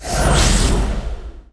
attack_eff.wav